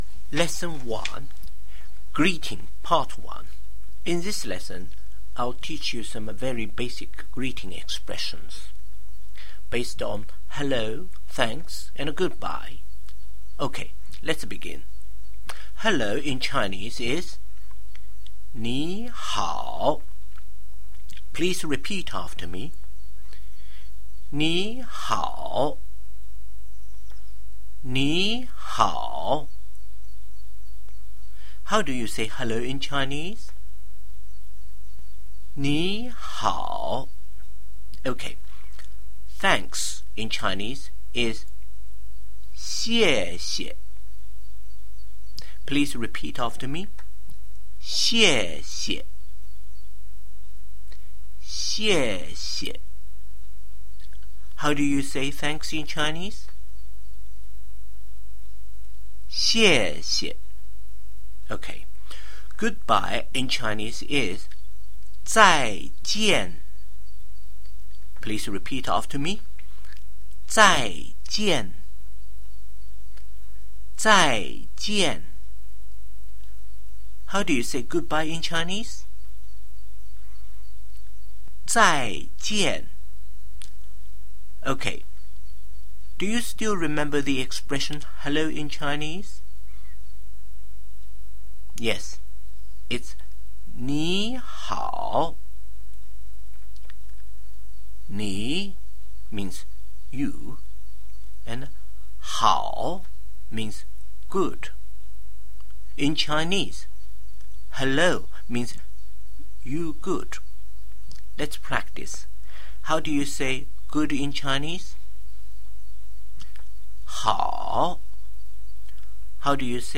Lesson One Greeting Part 1